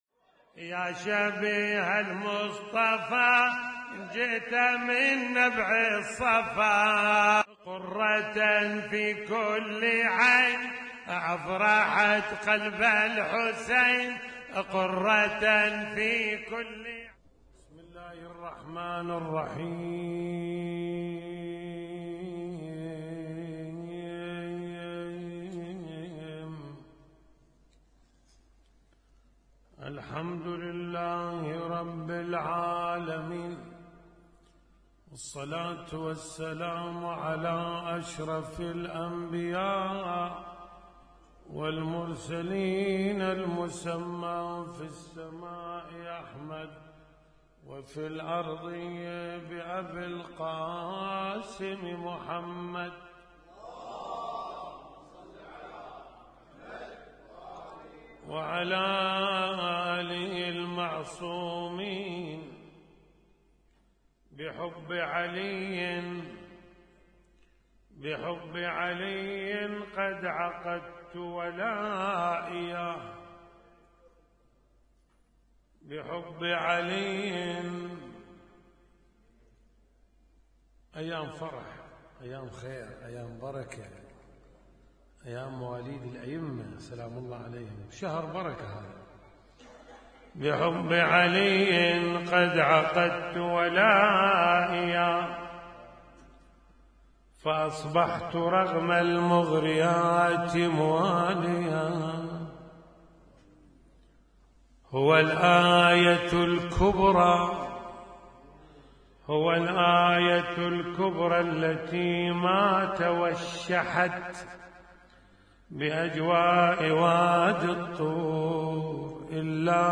اسم النشيد:: مولد علي الأكبر عليه السلام 1436
اسم التصنيف: المـكتبة الصــوتيه >> المواليد >> المواليد 1436